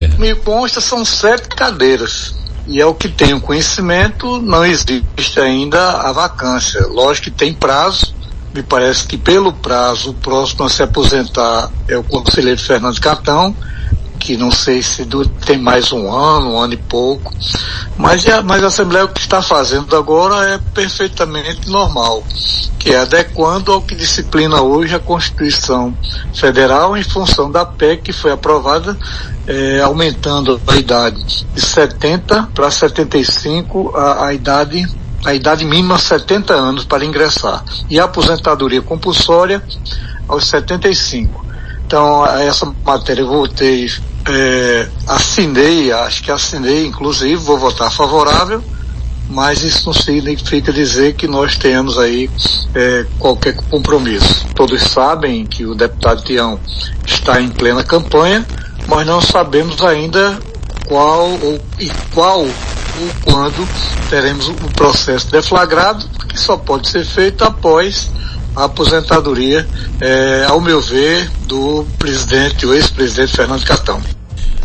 O deputado estadual Hervázio Bezerra, do PSB, também, endossou – durante entrevista nesta quinta-feira (09) a decisão da CCJ, na Assembleia Legislativa da Paraíba (ALPB) na tarde de ontem que aprovou, por unanimidade, a Proposta de Emenda Constitucional 01/23, de autoria do deputado Adriano Galdino (Republicanos), que disciplina a idade máxima para indicação ao cargo de 65 para 70 anos.
A declaração a Arapuan FM – o aponta como um possível pleiteante a essa vaga.